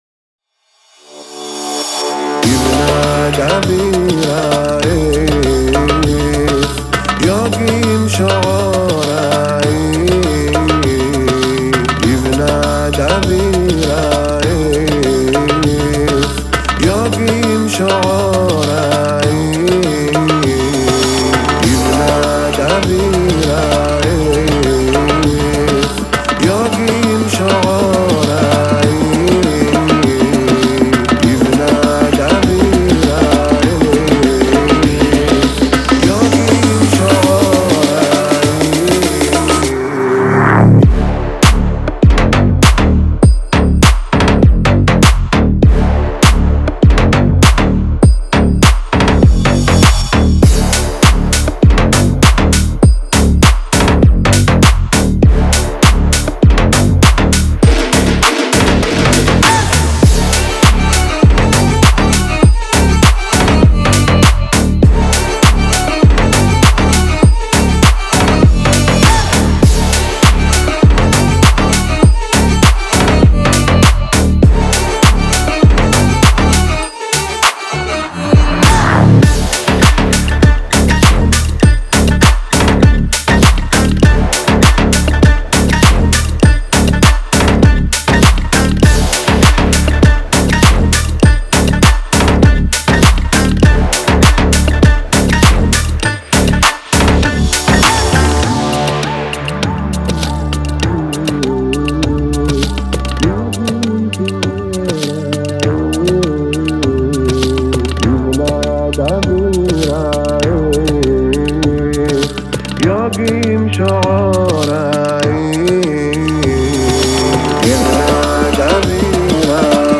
это энергичная композиция в жанре электро-хаус